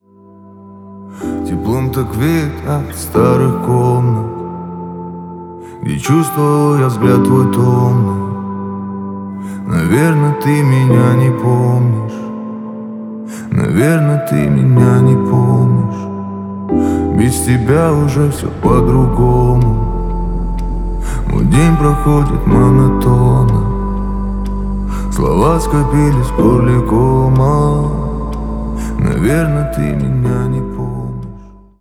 • Качество: 320, Stereo
грустные
спокойные
медленные
лирические